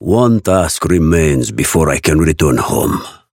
Raven voice line - One task remains before I can return home.